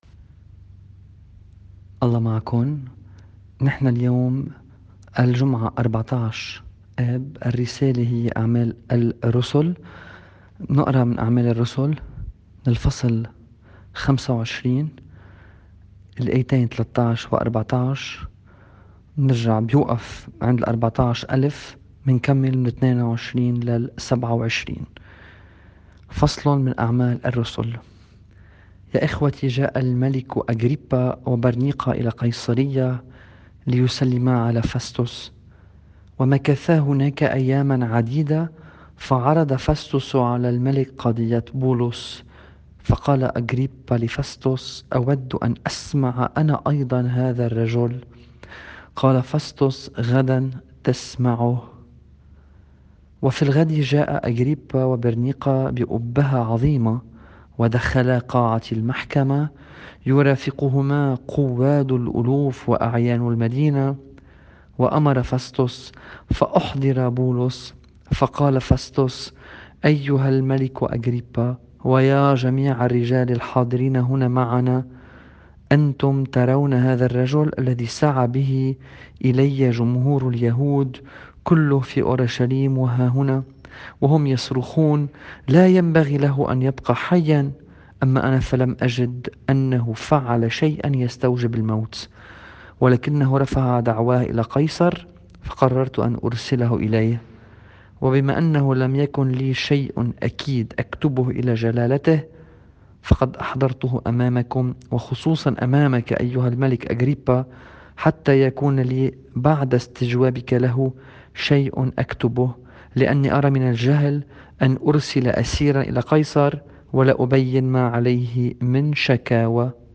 الرسالة بحسب التقويم الماروني :